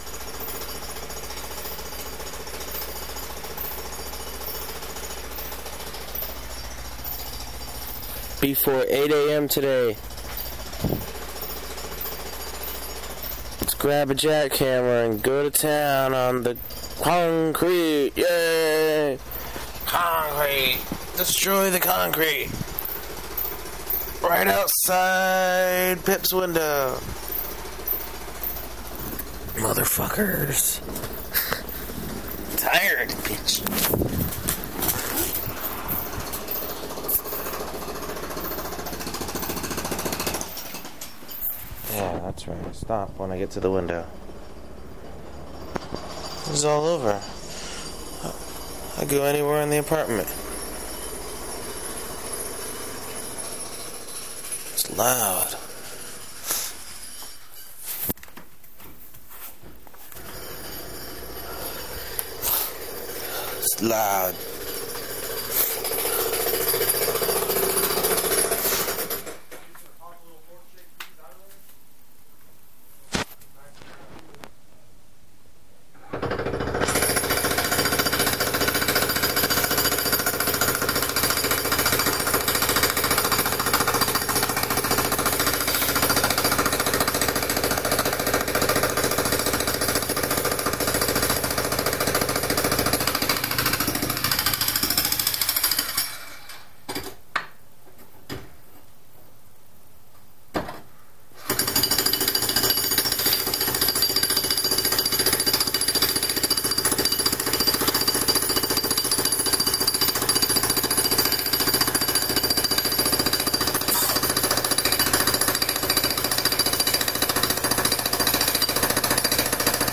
Share Facebook X Next Rain, some thunder, and what sounds like a cat having sex.